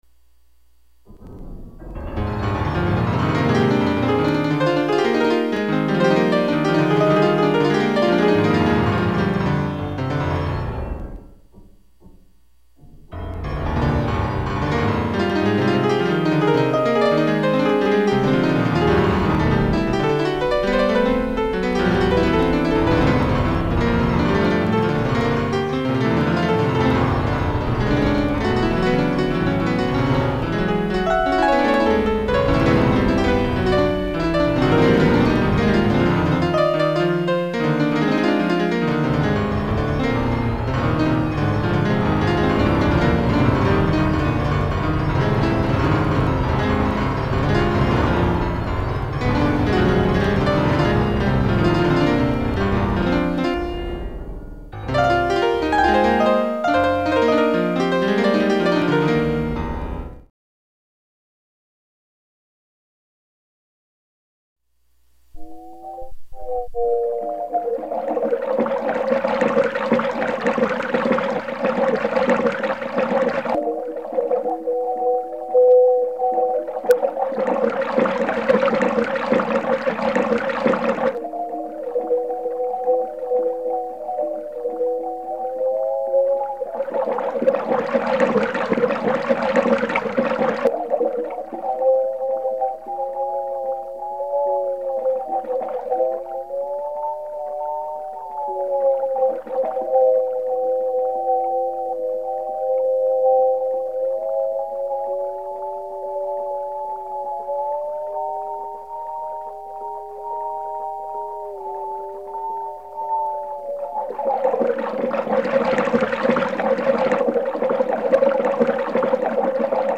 music compositions
SY85 is an all-in-one synthesizer produced by YAMAHA in 1992.
My first and longest experience of multitrack sequencer was on SY85.